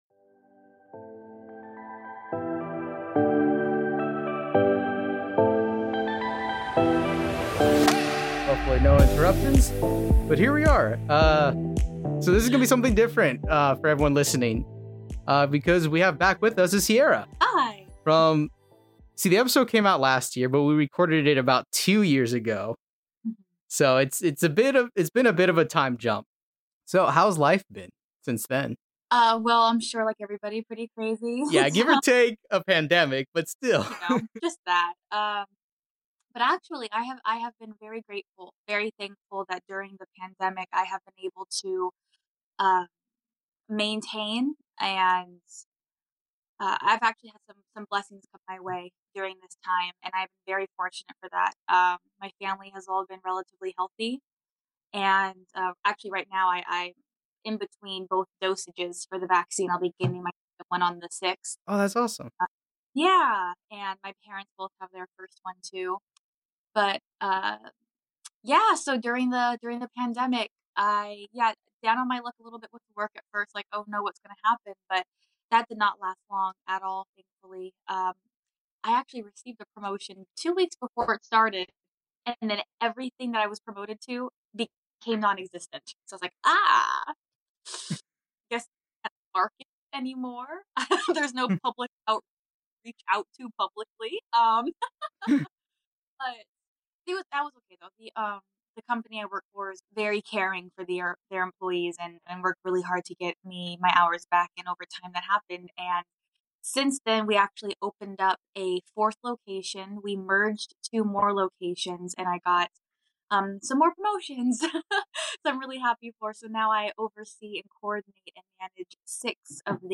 *Apologies for some of the audio cutting in and out. Had some wifi issues while recording.*
*There are a few moments during this where we are both completely silent*